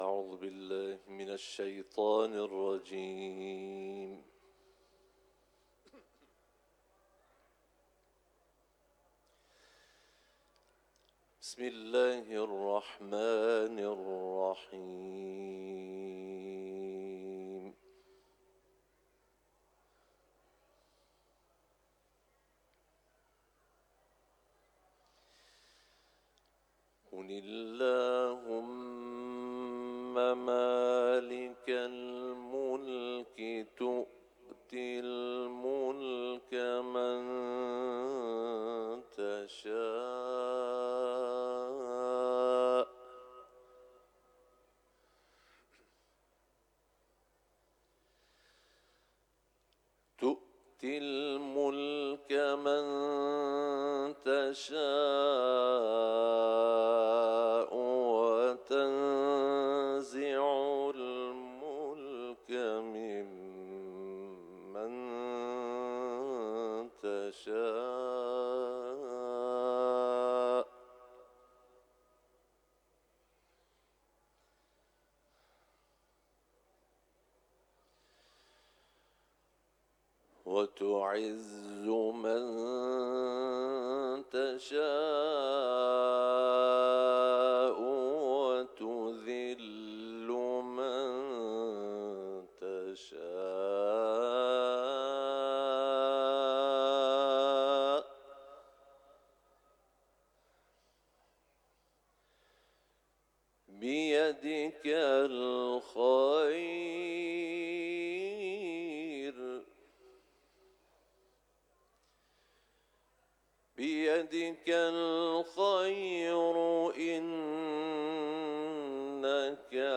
سوره آل عمران ، تلاوت قرآن